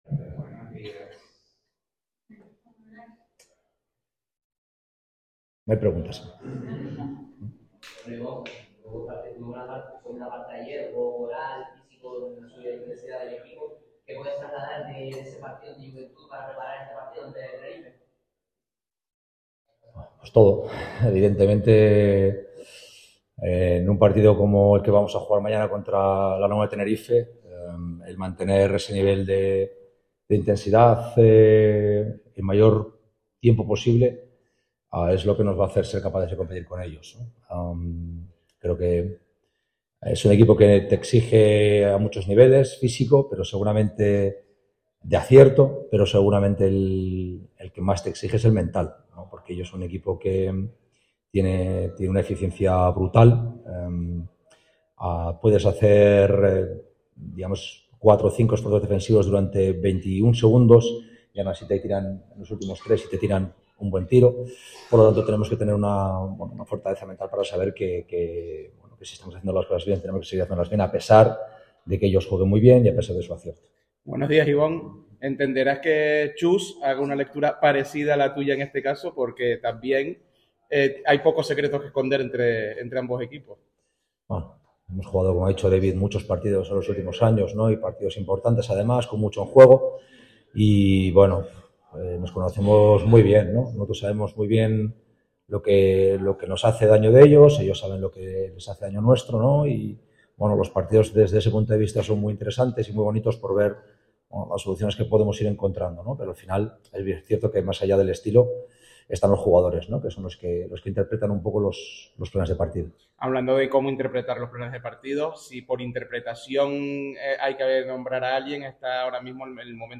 Ibon Navarro compareció ante los medios en la previa del partido de semifinales ante La Laguna Tenerife.